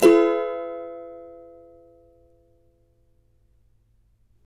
CAVA F#MJ  U.wav